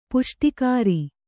♪ puṣṭikāri